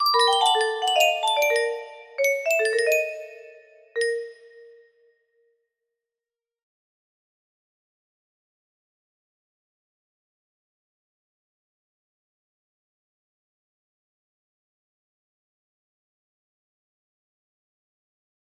Grande illusion 30 music box melody